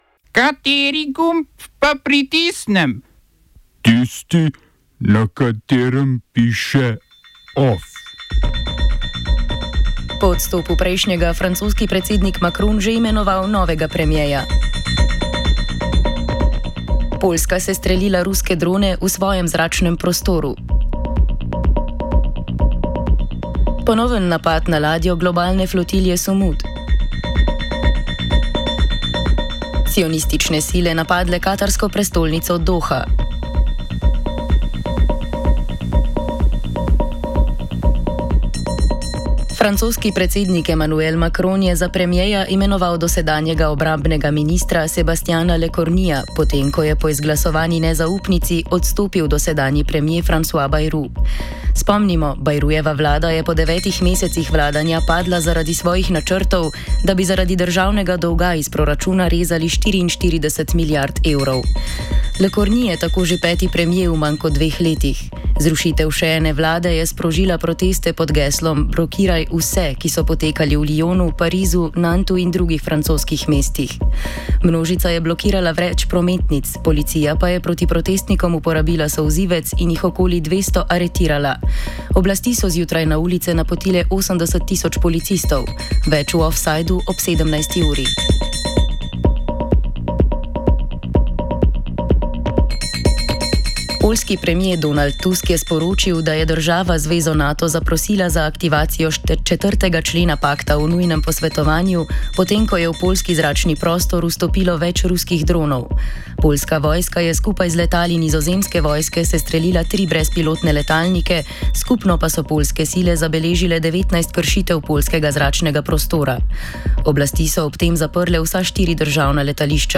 Novičarska informativna oddaja aktualnopolitične redakcije. Novice zadnjega dne, ki jih ne smete spregledati.